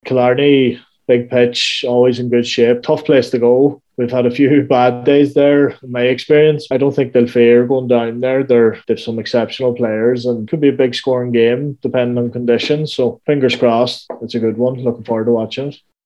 Speaking after the Electric Ireland Sigerson Cup Final that saw NUIG crowned champions after a hard-fought win over UL, the former Donegal shot stopper is expecting a high scoring game if weather conditions are favourable.